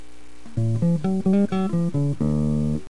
1B (bass)